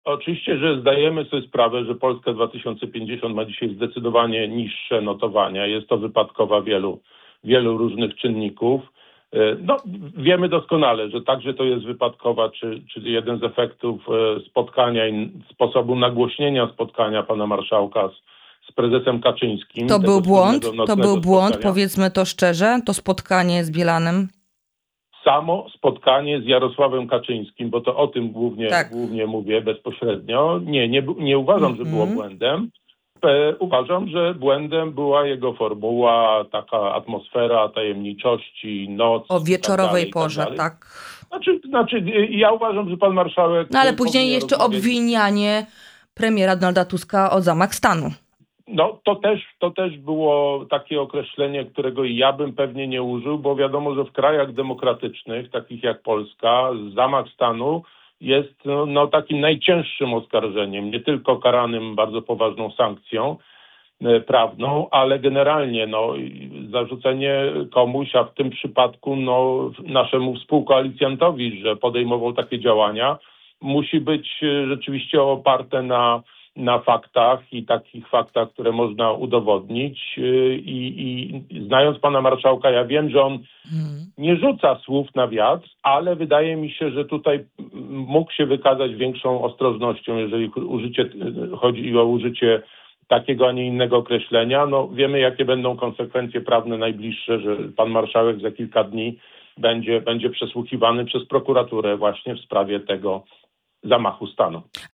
O sytuacji w partii, potencjalnych odejściach, ewentualnej tece wiceministra, a także głosowaniu nad odwołaniem przewodniczącej Komisji Europejskiej rozmawiamy z europosłem Krzysztofem Kobosko.